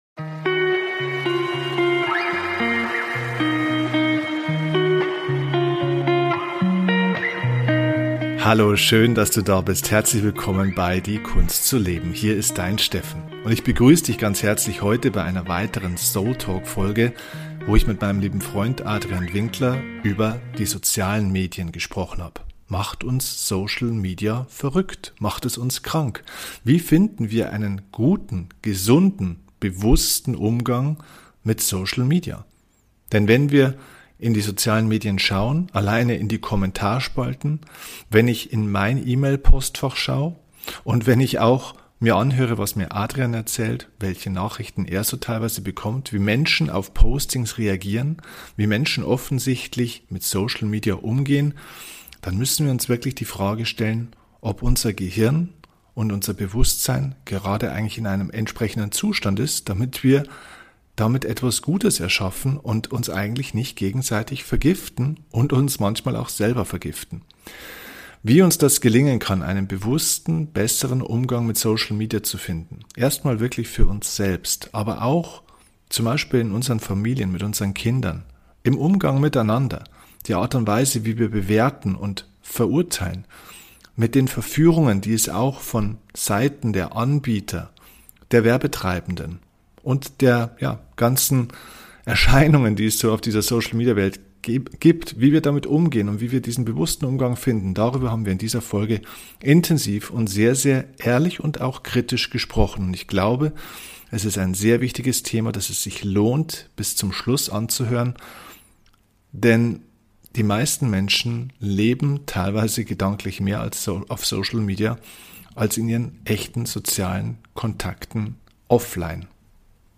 Wie immer bei diesem Format: Es gibt kein Skript, nur Gedanken zweier Freunde.